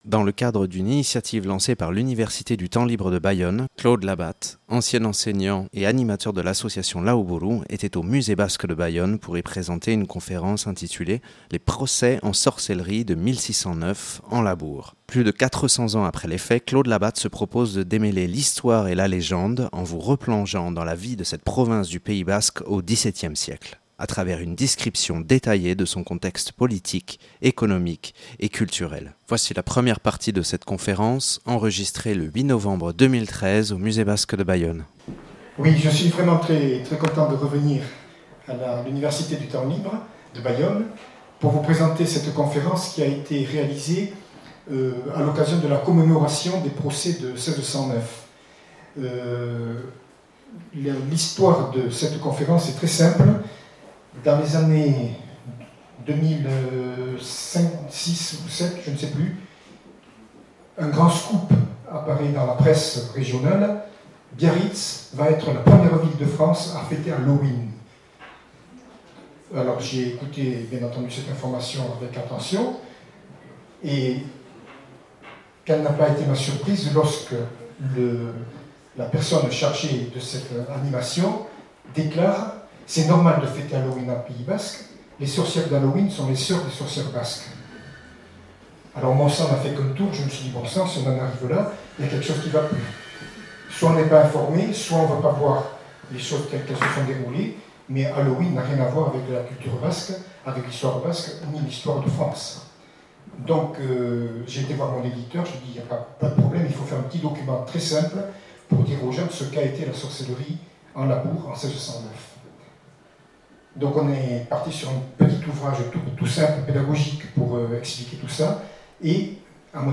Enregistrée au Musée Basque et de l’histoire de Bayonne le 08/11/2013.